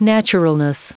Prononciation du mot : naturalness